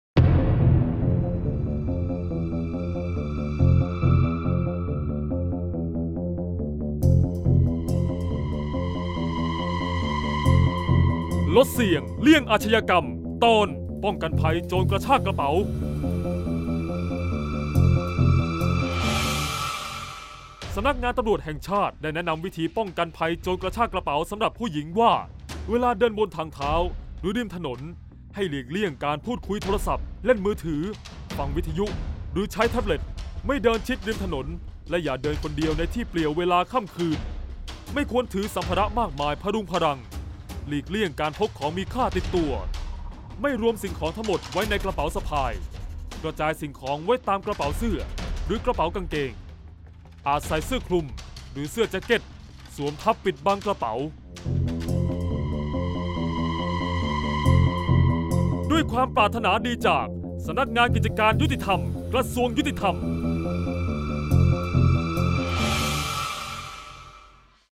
เสียงบรรยาย ลดเสี่ยงเลี่ยงอาชญากรรม 36-ป้องกันกระชากกระเป๋า